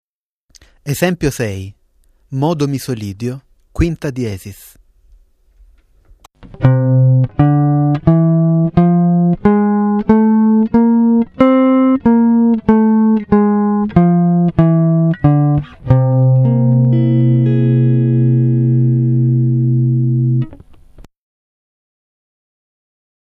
7(#5) con Misolidio #5